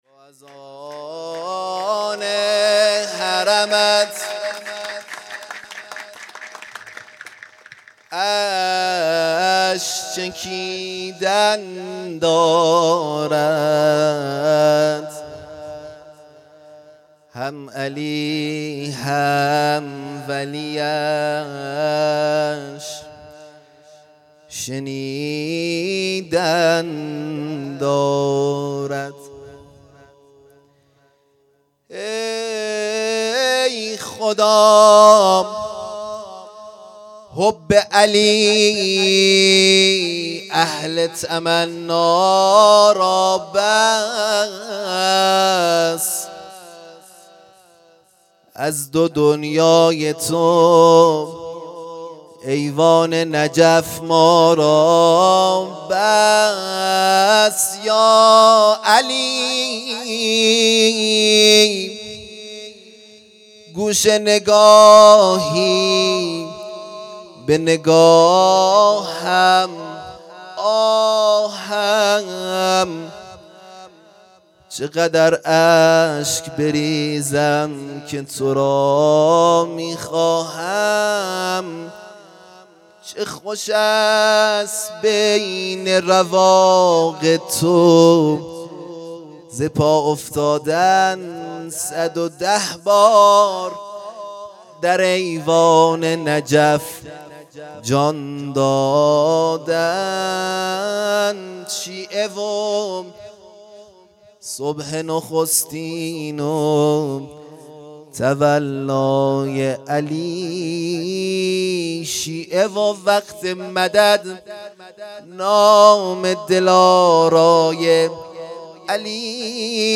خیمه گاه - هیئت بچه های فاطمه (س) - مدح | چقدر اشک بریزم که تو را میخواهم
ویژه برنامه ولادت حضرت زینب(س)